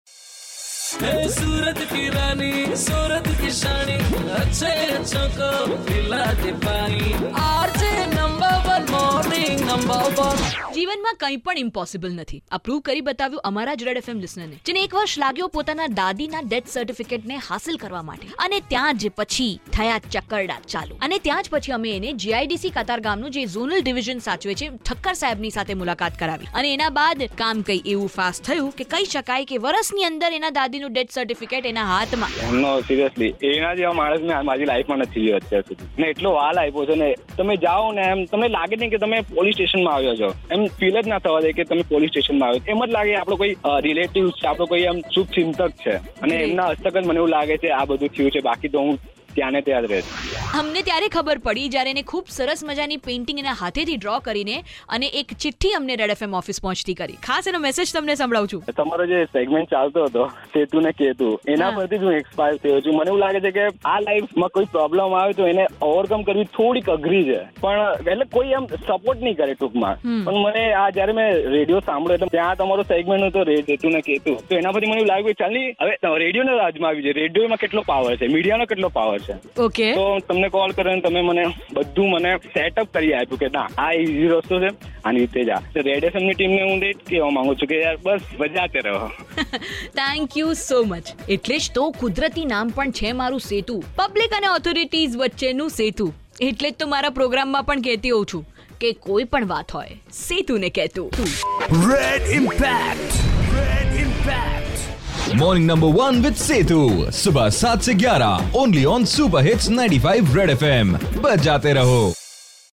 IN CONVERSATION WITH A LISTENER